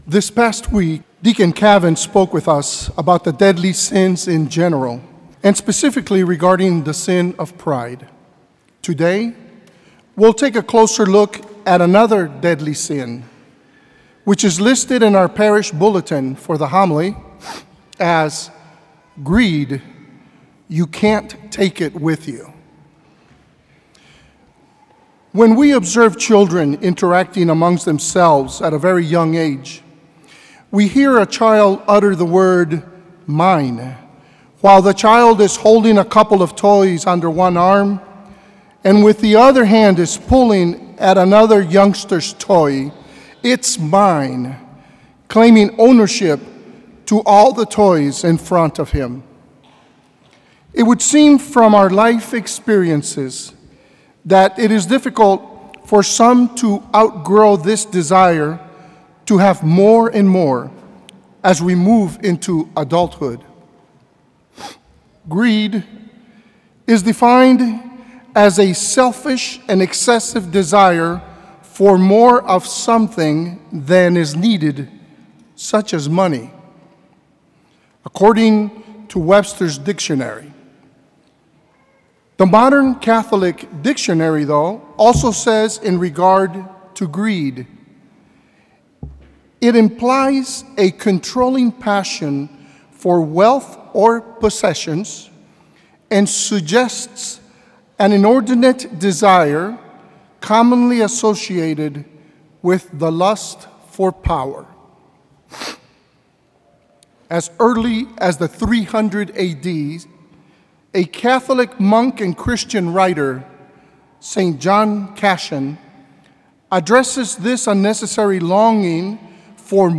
A homily from the series "The Seven Deadly Sins."